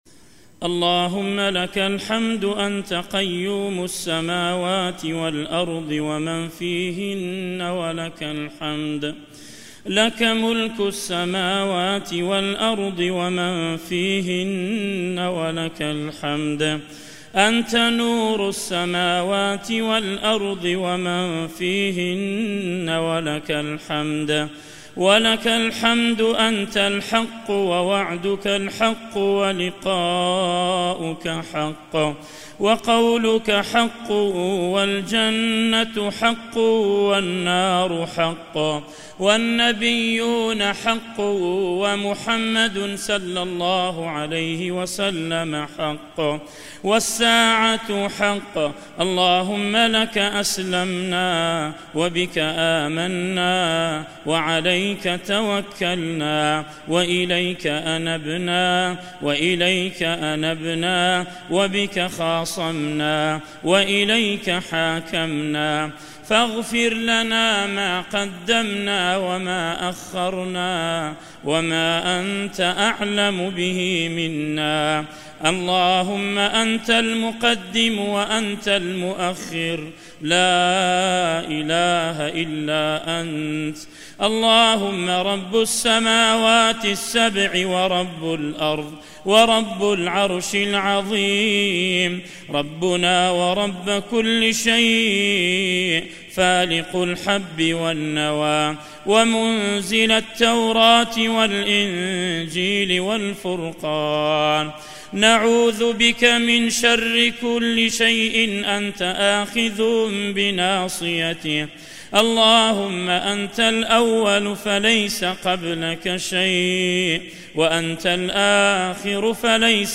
دعاء خاشع ومؤثر
دعاء مؤثر وعذب.